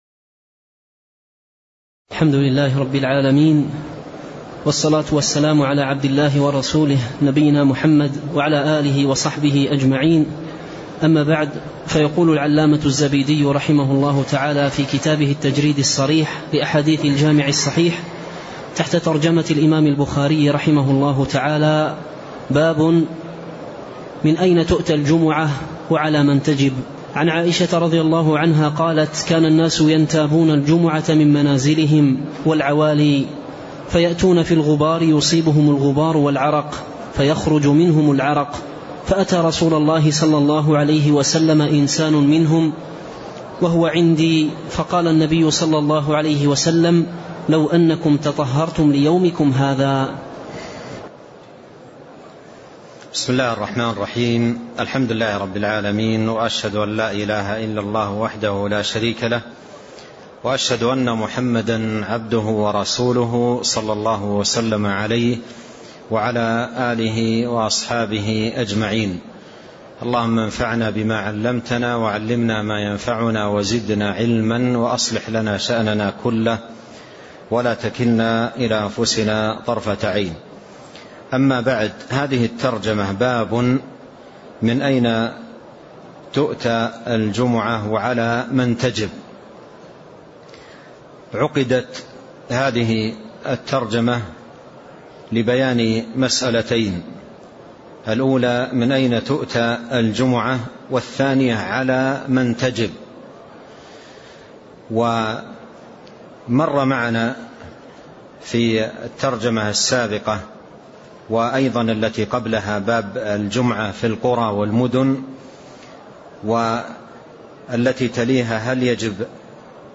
تاريخ النشر ١٦ ربيع الثاني ١٤٣٤ هـ المكان: المسجد النبوي الشيخ